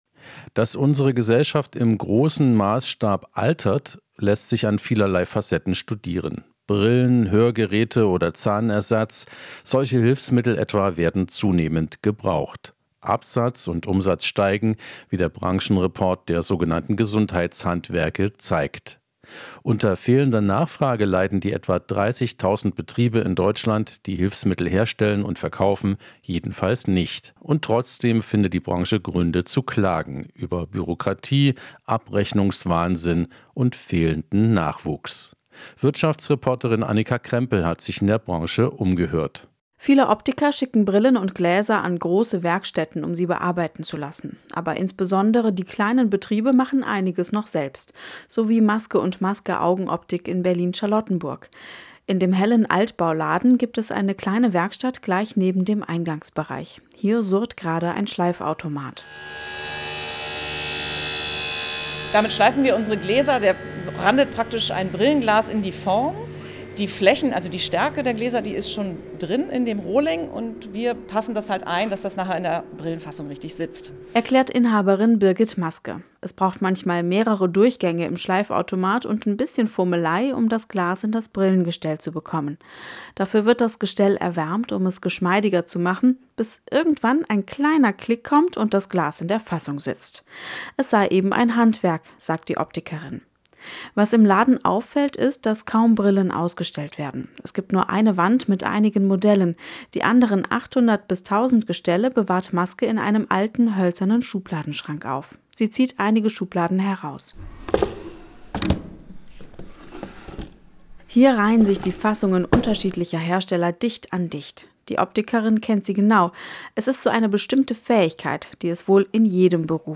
Die Wirtschaftsreportage - Gesundheitshandwerk zwischen Boom und Krise